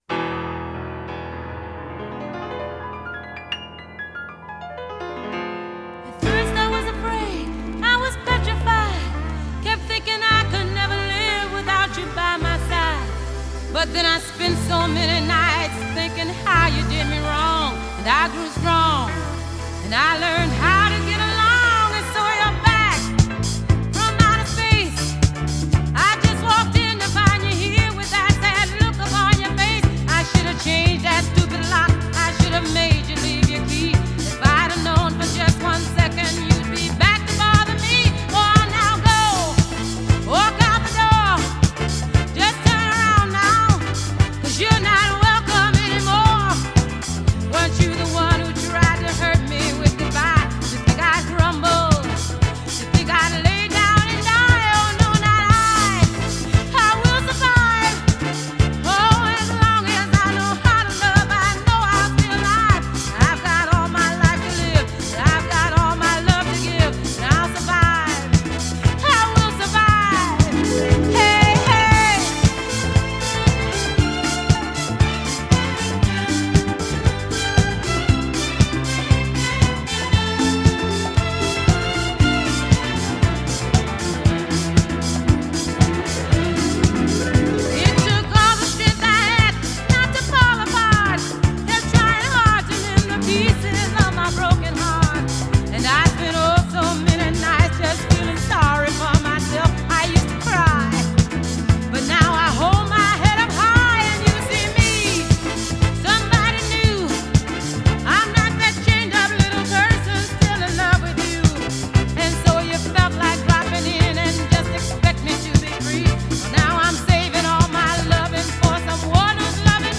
Disco (Music)
Disco music